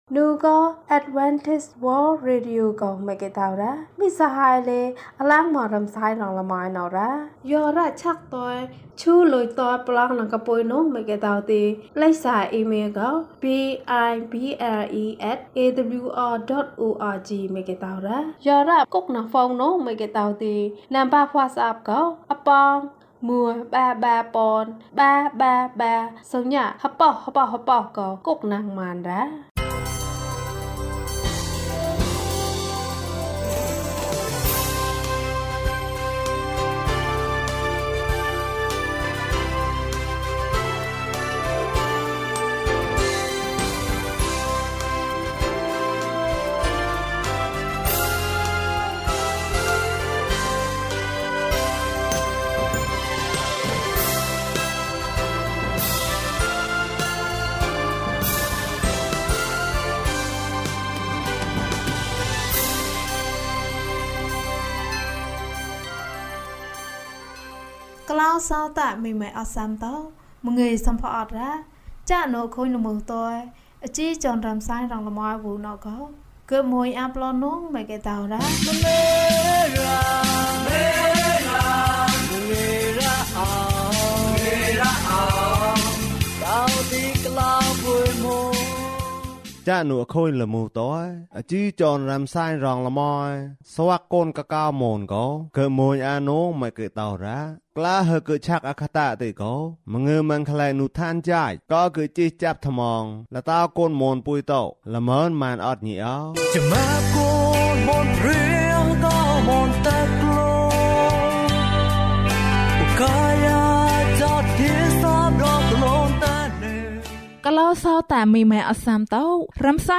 ယေရှုသည် ကျွန်ုပ်၏ကယ်တင်ရှင်ဖြစ်သည်။၀၁ ကျန်းမာခြင်းအကြောင်းအရာ။ ဓမ္မသီချင်း။ တရားဒေသနာ။